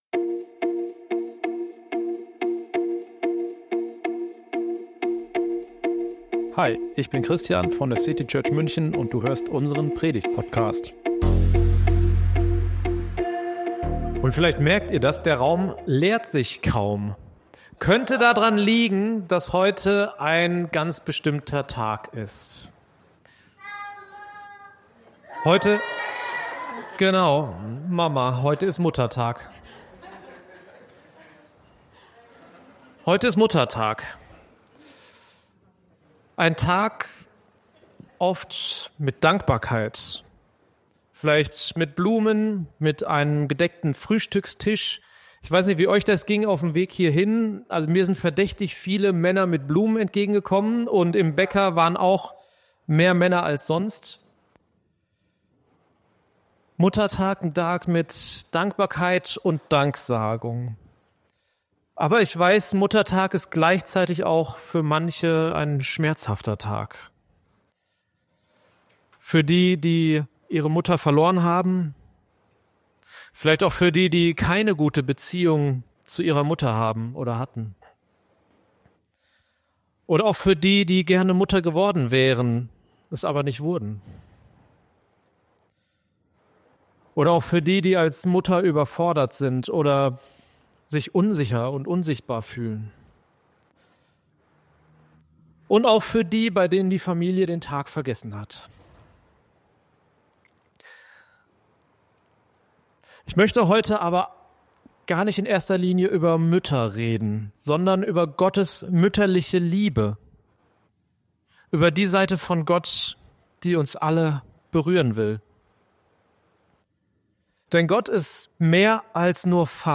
Ein Gottesdienst über eine ganz besondere Seite Gottes.